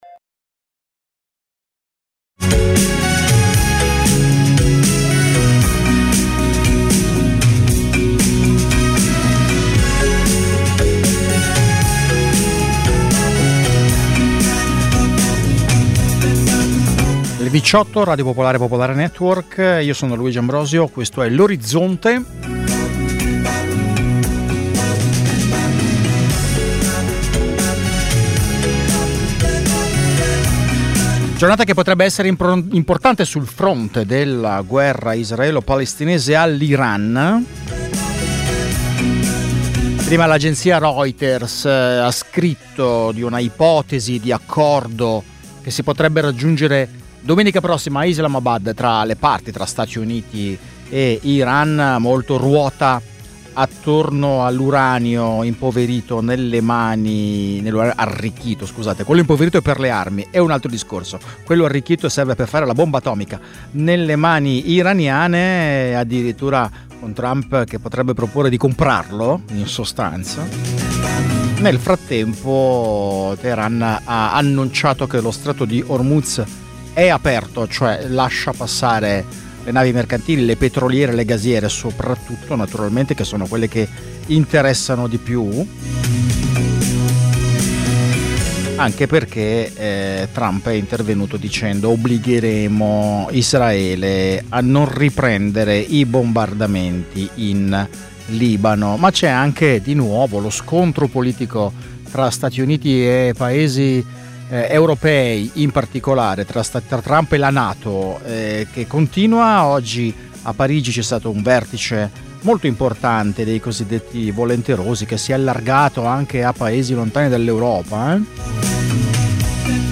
Dalle 18 alle 19 i fatti dall’Italia e dal mondo, mentre accadono. Una cronaca in movimento, tra studio, corrispondenze e territorio. Senza copioni e in presa diretta.